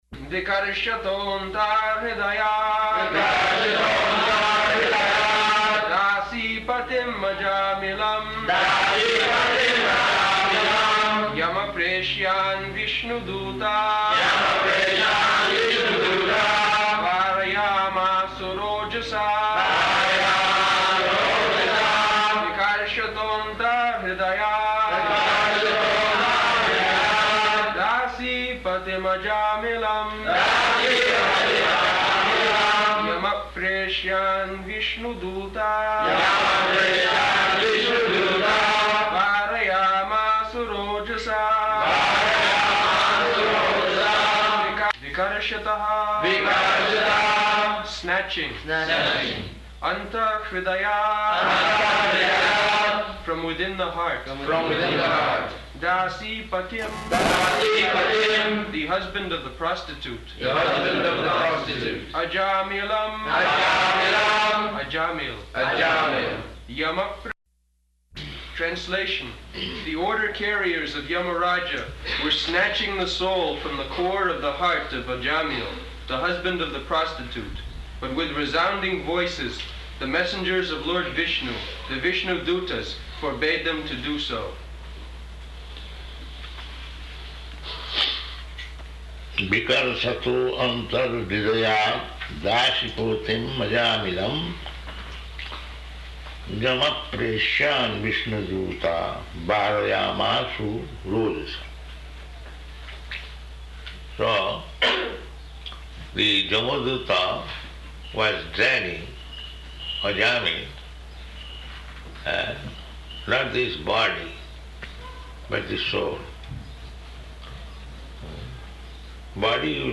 Location: Honolulu
[leads devotees in chanting]